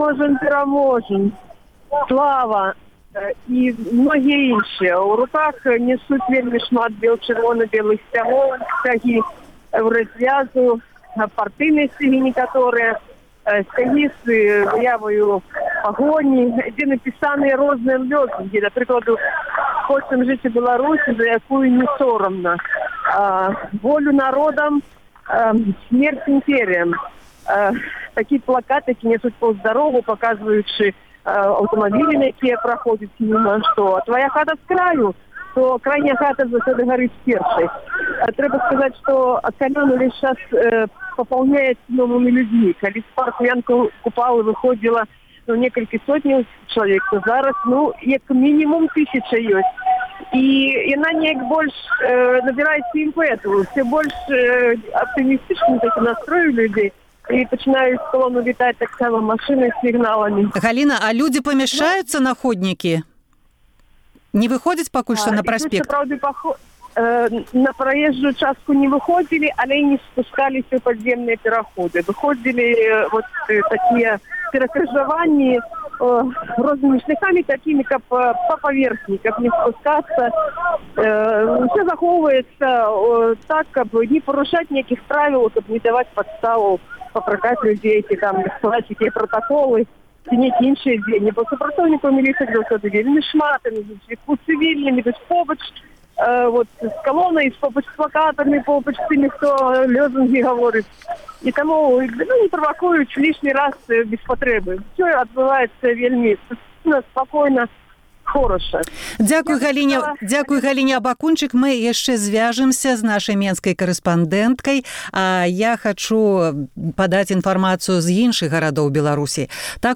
Жывы эфір зь Менску і Прагі